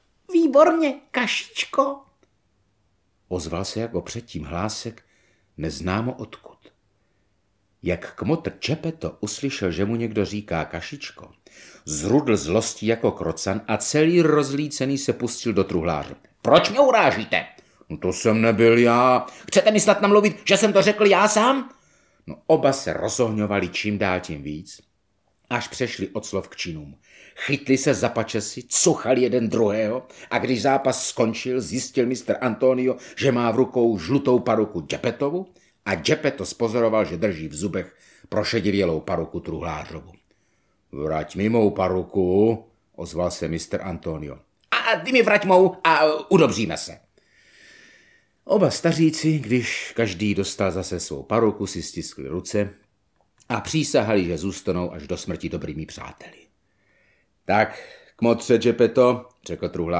Audiobook